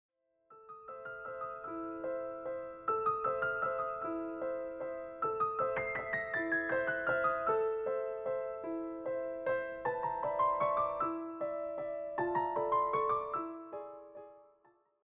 presented in a smooth piano setting.
steady, easygoing tone